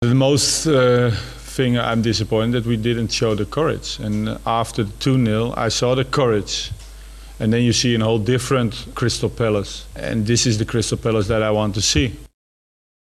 Crystal Palace manager on his team after their match against Swansea City